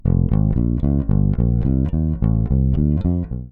Guitare Basse